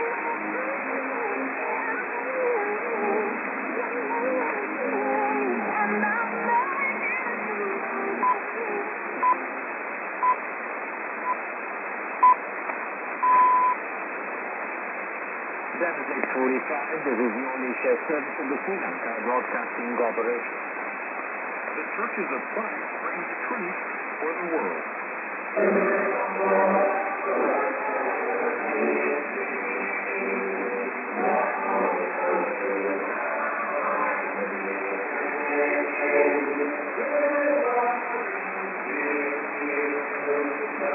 music->TS->ANN(man:ID)->prog